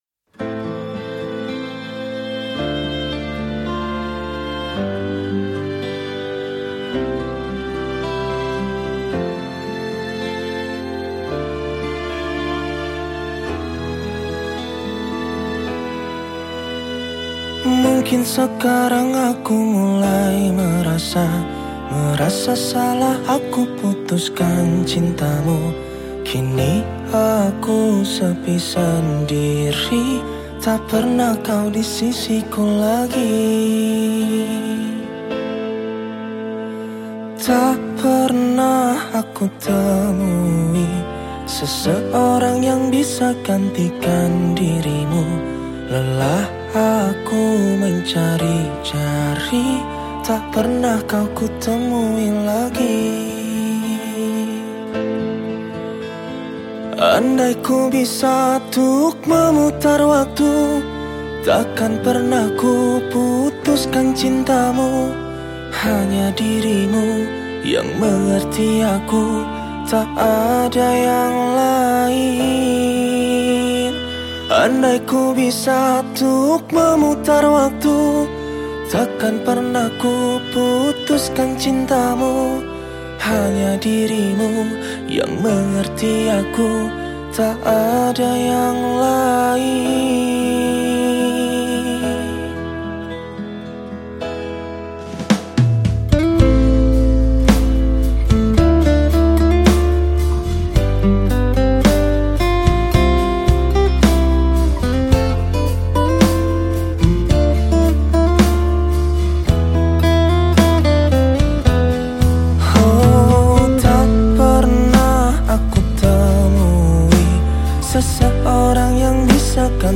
Genre Musik                             : Pop